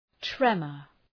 Προφορά
{‘tremər}